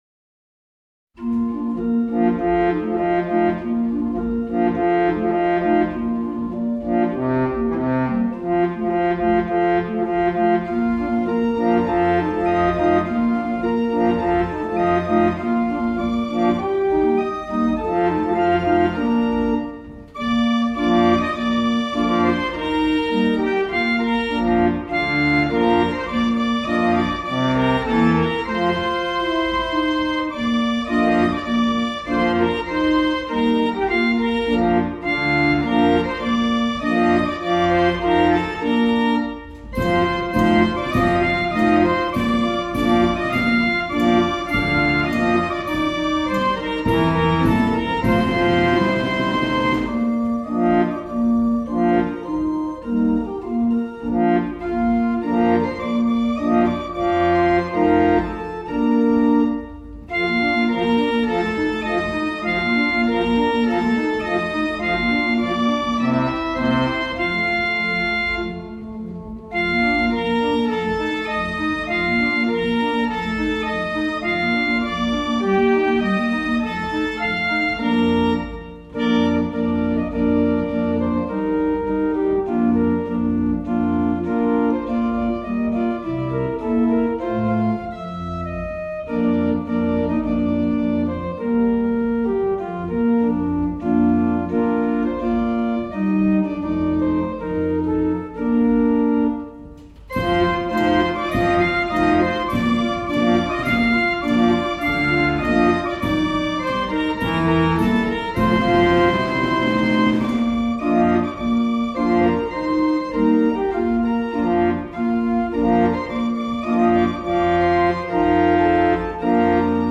“奥开斯特里翁”机械风琴
当时，这项发明在科技上极端先进，据说这座巨大的自动乐器远近驰名，由于它成功地模拟出管弦乐团的许多声音，大众称之为“奥开斯特里翁”（机械管弦乐团的谐音）。
光是内建的整组机械管风琴，就由180支的金属管及木管组成，此外还有各种大小的鼓、三角铁，以及五花八门的发声装置，全部安装在外观高雅的橡木制机身中。
mechanical-organ-orchestrion.mp3